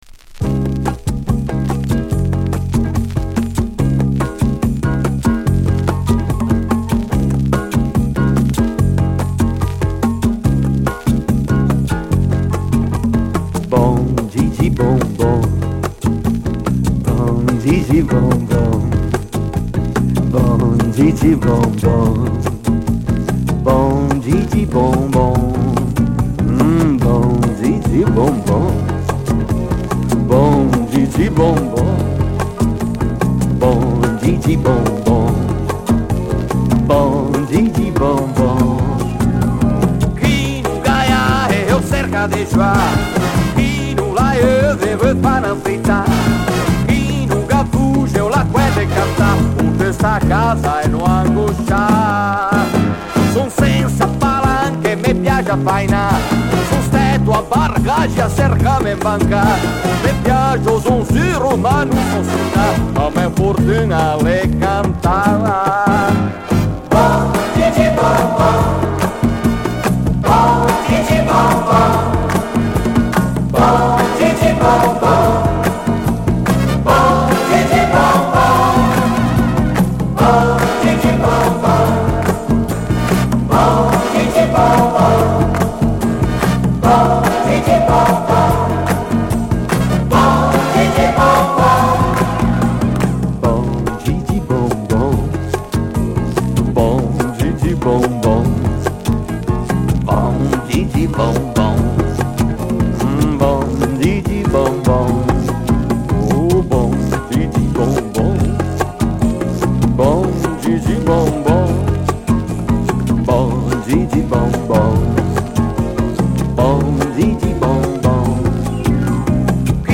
Jazz Groove italy
両面ともラテン・ブラジリアン・テイストを散りばめた、ひとクセあるジャズ・グルーヴになっています。
※全体的にチリ音が出ます（試聴にてご確認ください）。